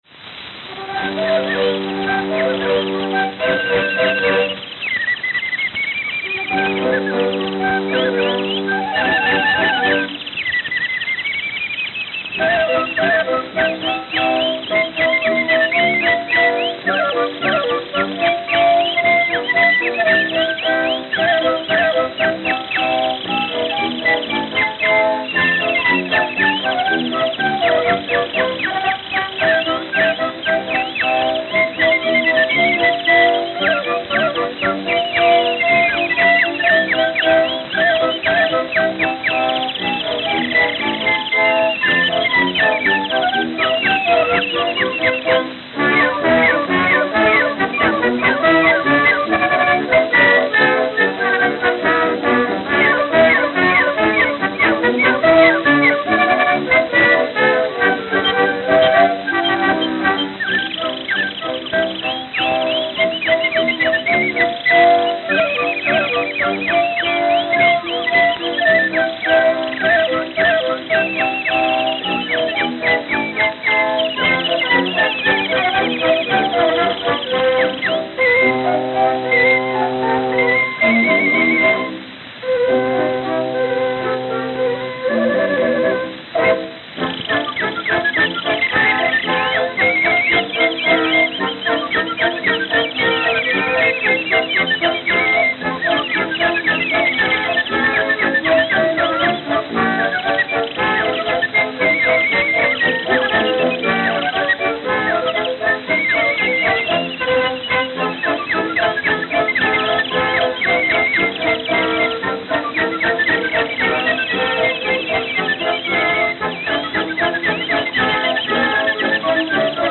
Philadelphia, Pennsylvania (?)